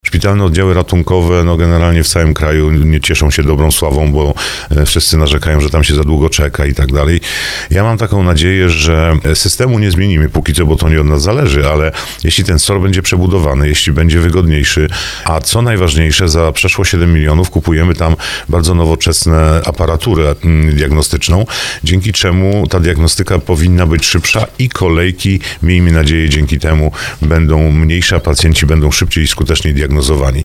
– Trzeba przetrwać niedogodności, żeby później było lepiej – tak o trwającym remoncie SOR-u brzeskiego szpitala mówi starosta brzeski Andrzej Potępa.